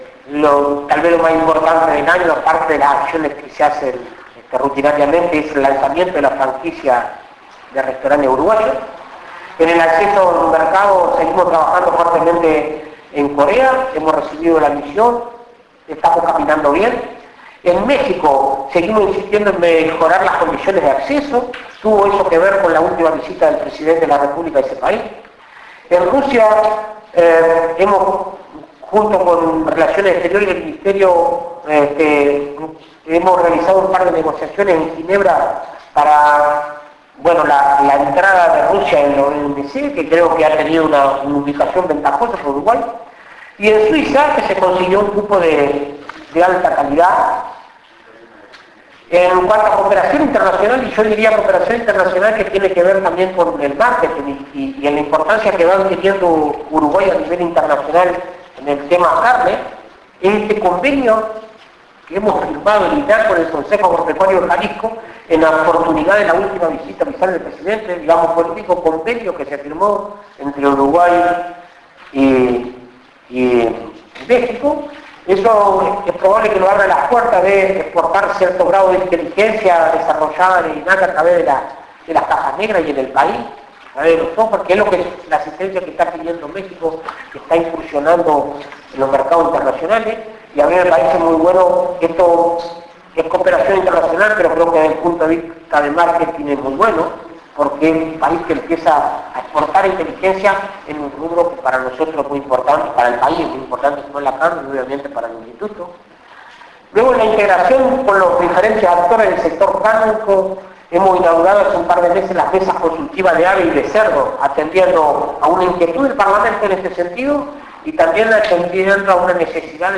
Presidente de INAC Conferencia de prensa. proyectos 2011 9:29 Mp3